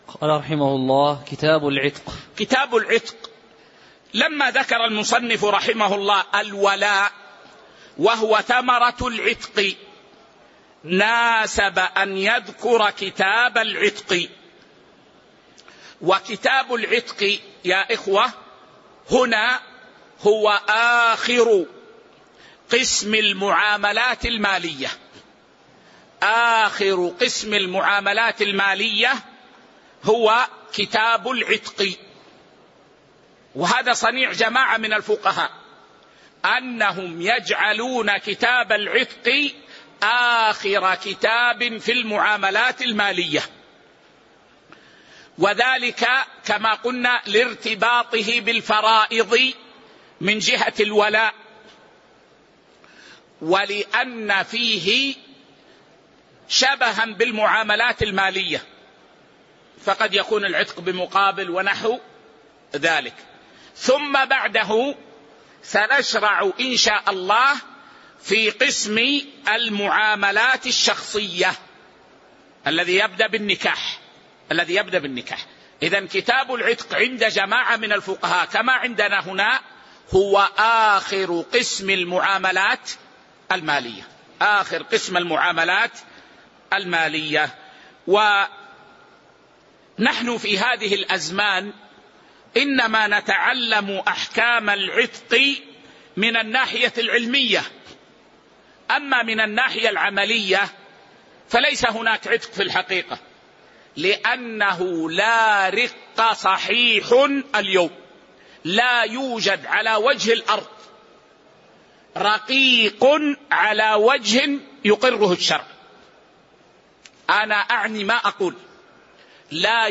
تاريخ النشر ١٢ ربيع الثاني ١٤٤٥ هـ المكان: المسجد النبوي الشيخ